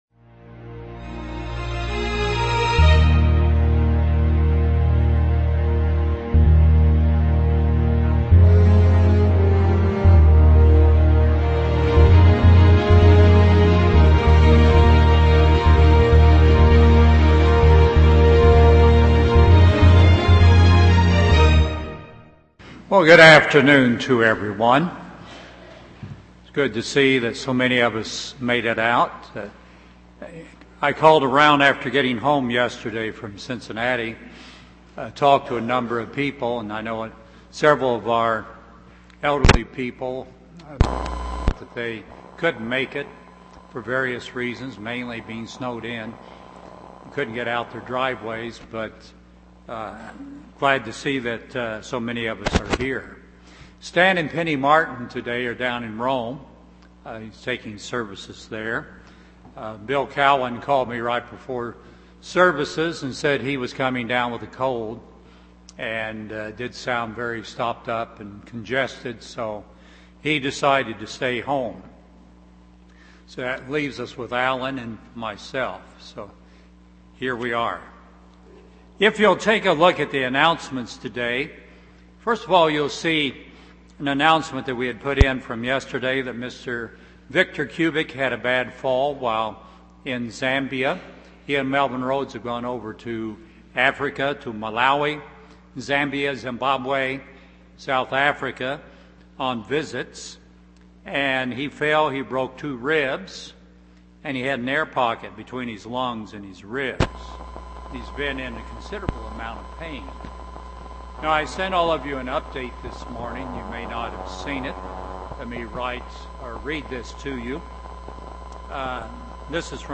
He is the only stable foundation. 1 Corinthians 3:11 UCG Sermon Transcript This transcript was generated by AI and may contain errors.